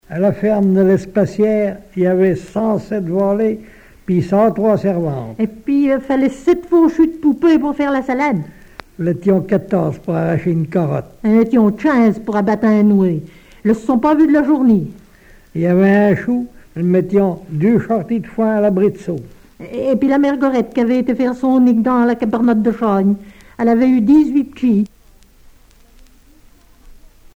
conte de mensonges
Genre conte
Catégorie Récit